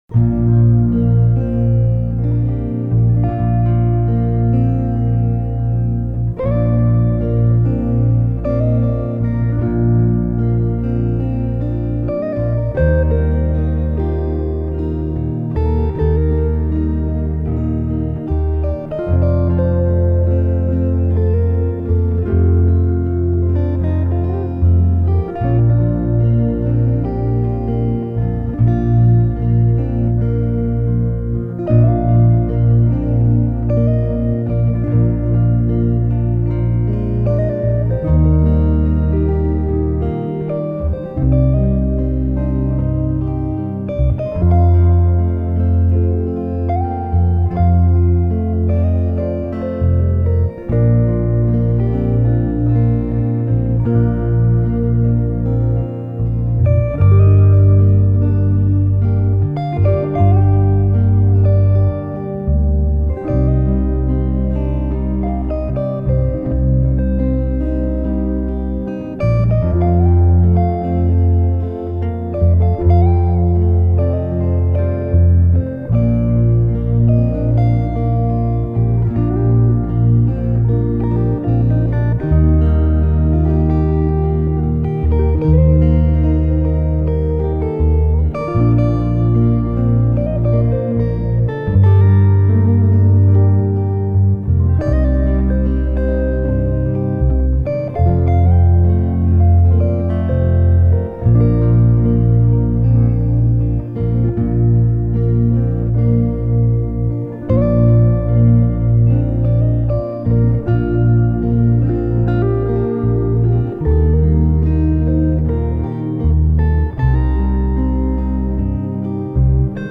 آرامش بخش
پیانو , گیتار الکترونیک